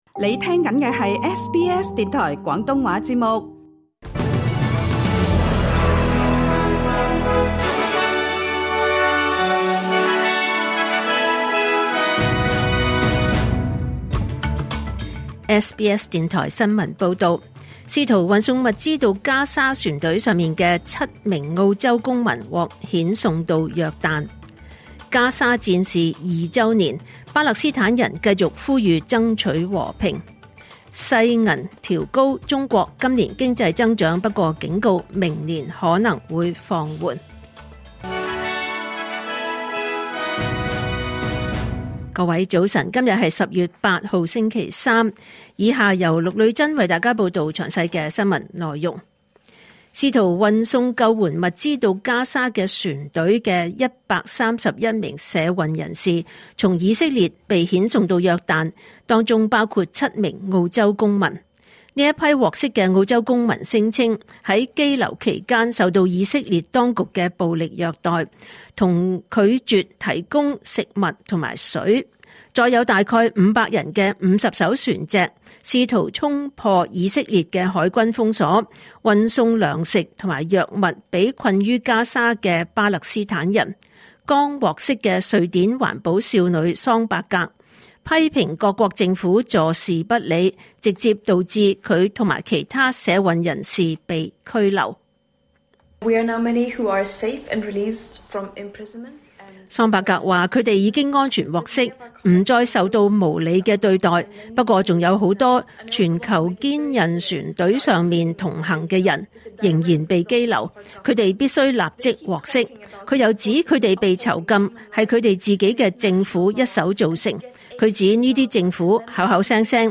2025年10月8日SBS廣東話節目九點半新聞報道。